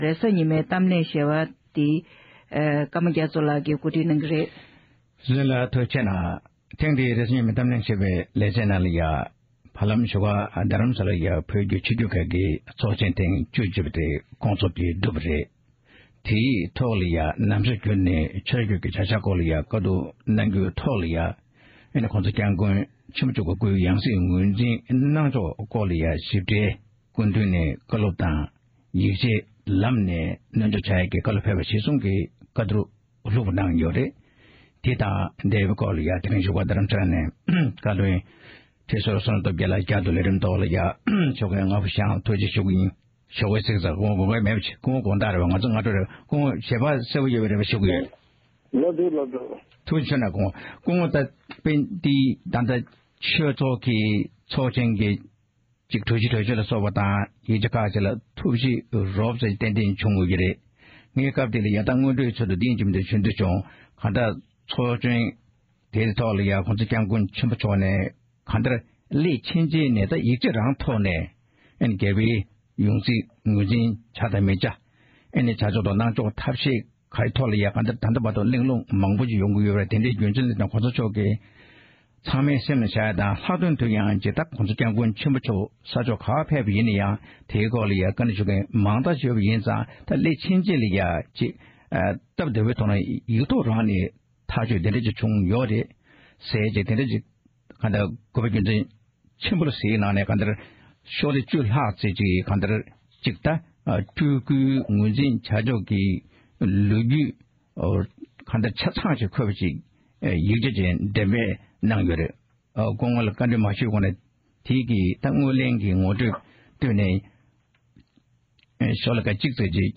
གླེང་མོལ་